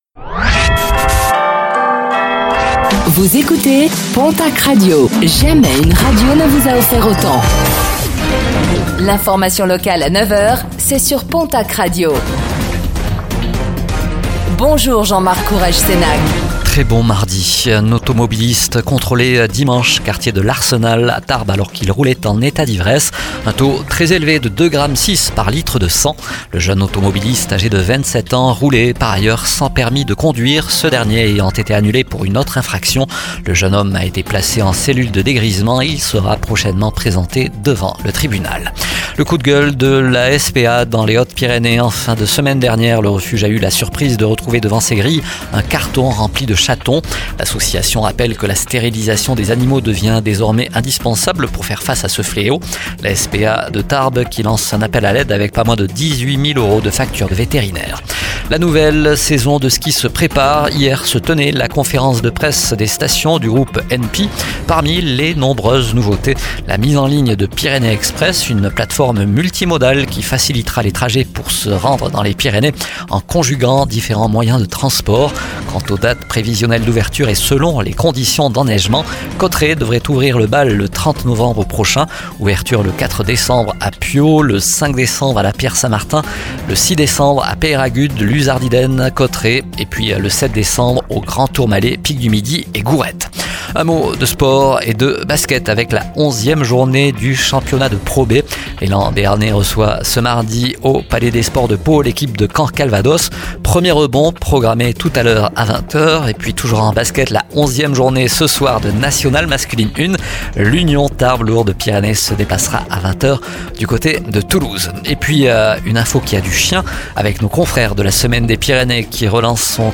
Réécoutez le flash d'information locale de ce mardi 05 novembre 2024